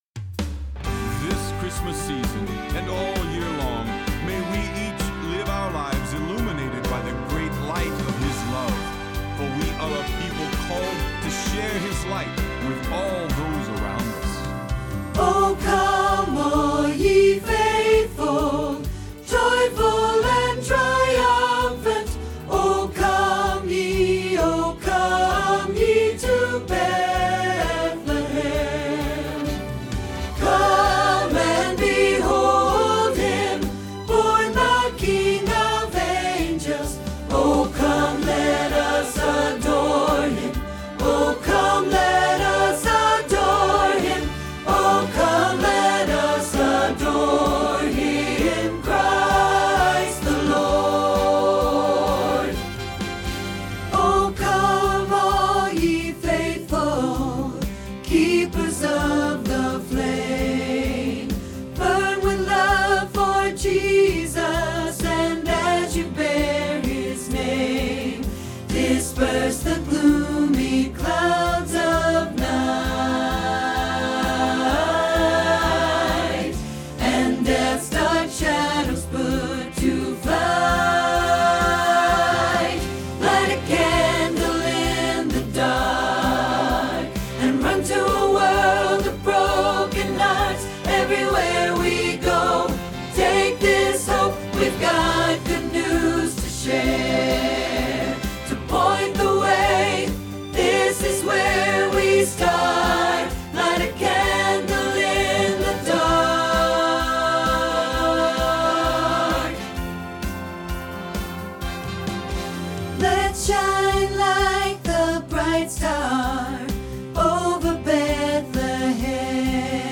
Alto Practice Trax